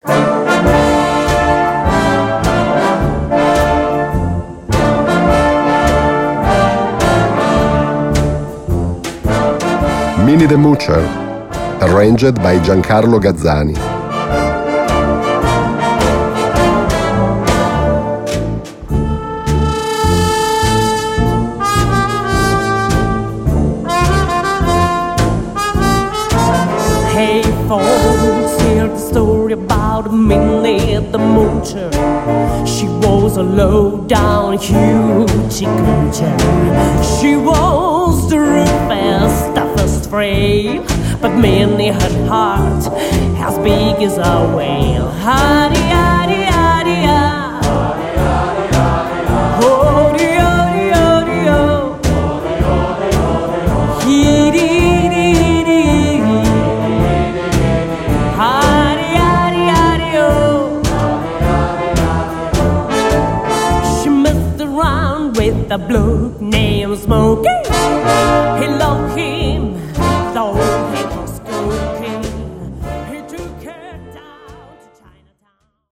Partitions pour orchestre d'harmonie, ou fanfare.